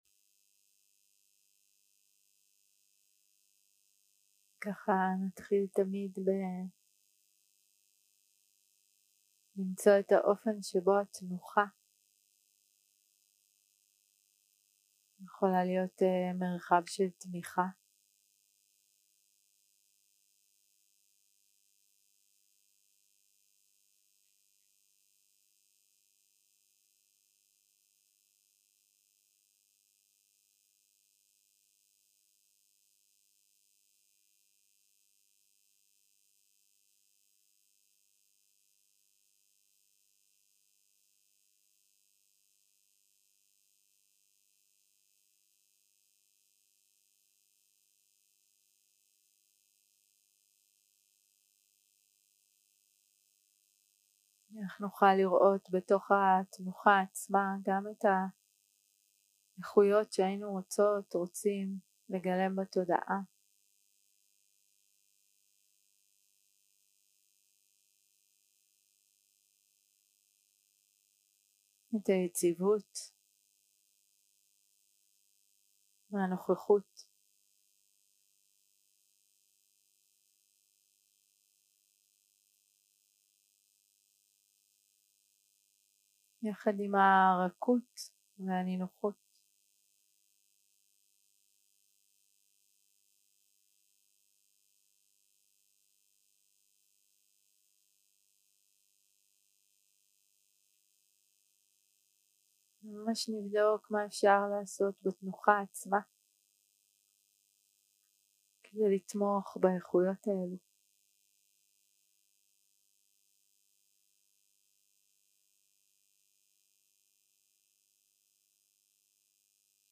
Guided meditation שפת ההקלטה